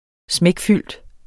Udtale [ ˈsmεgˈfylˀd ]